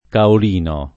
[ kaol & no ]